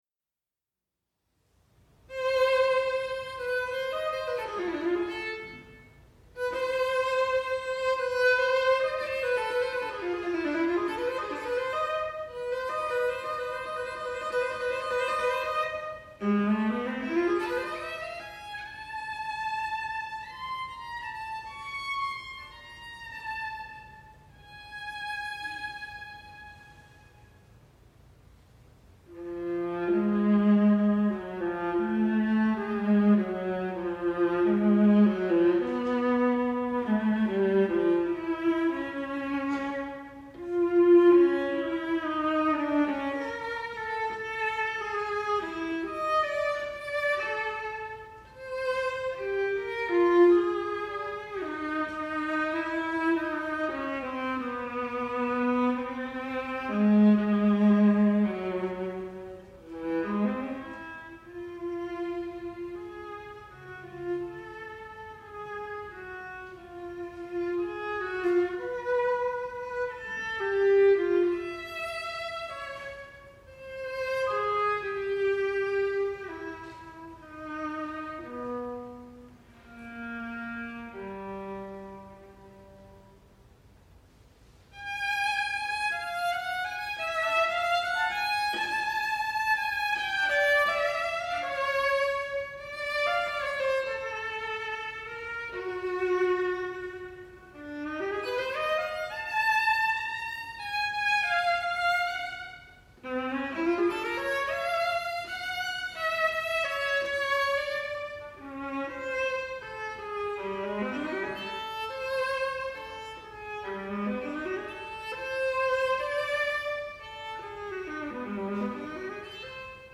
Except as noted, you will hear the composer playing viola,
for solo viola $4.00
a contemplative, lyrical soliloquy and a lively
Krakovian dance in the spirit of Polish folk music;